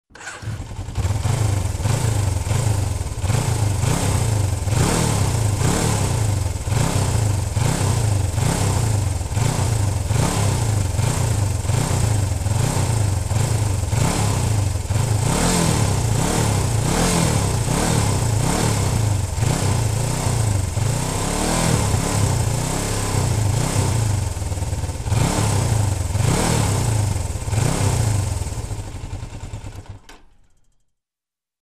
Мотоцикл заводится, перегазовка, глушение
• Категория: Мотоциклы и мопеды
• Качество: Высокое